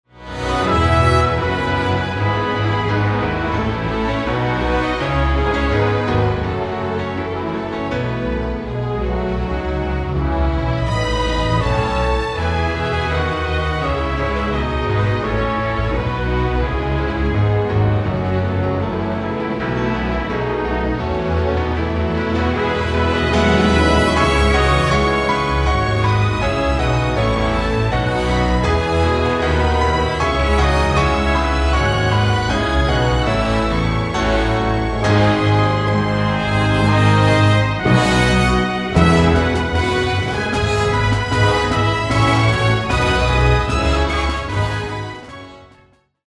performance track
Instrumental